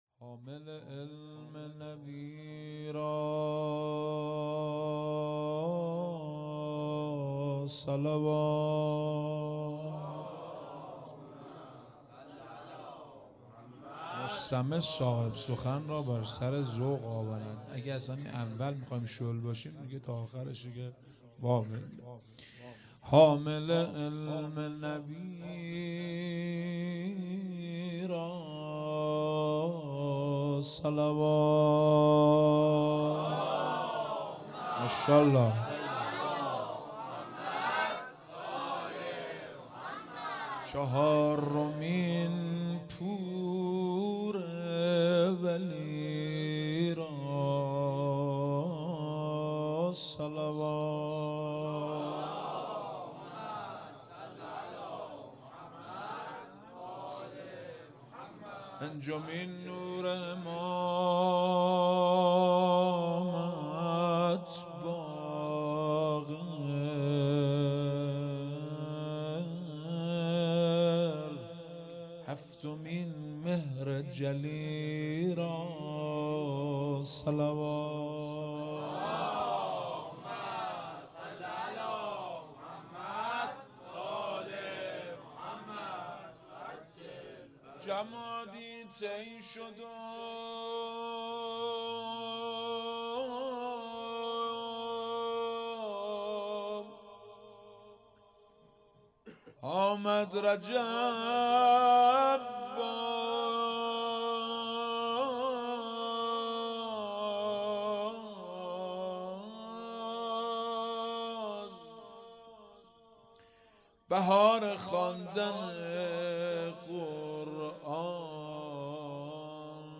ولادت امام باقر علیه السلام